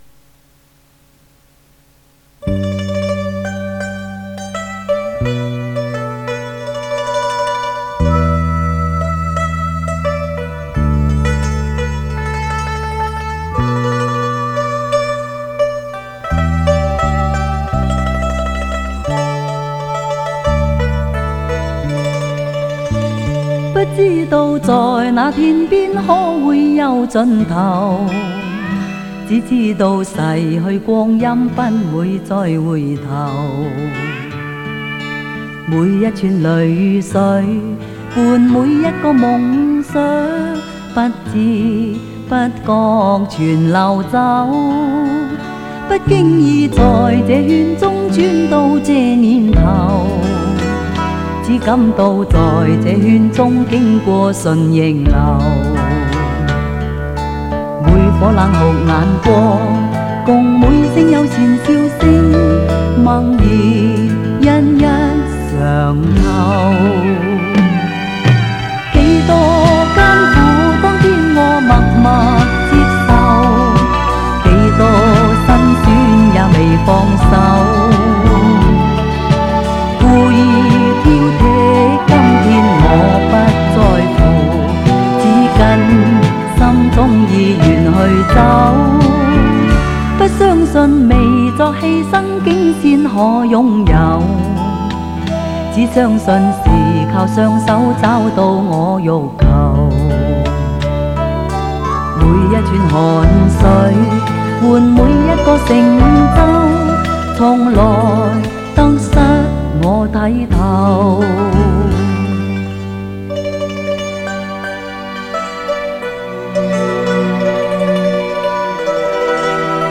磁带数字化：2023-02-25